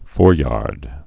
(fôryärd)